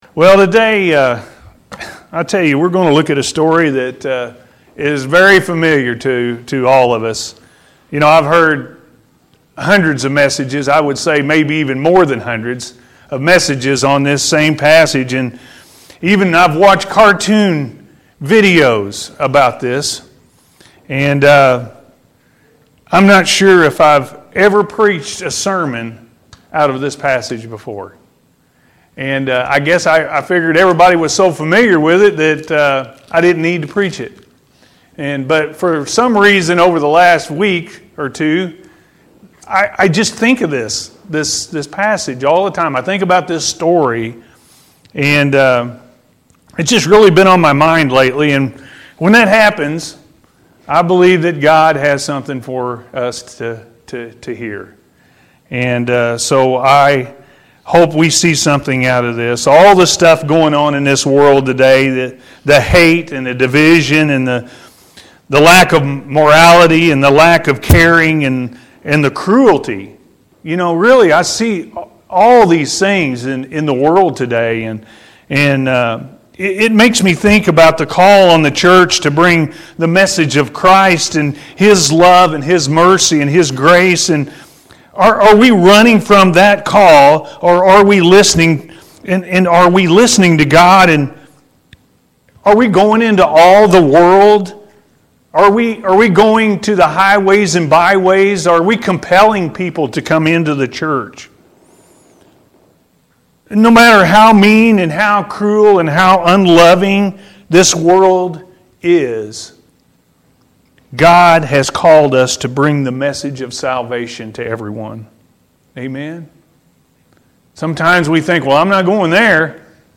God Calls Us To Share Our Salvation-A.M. Service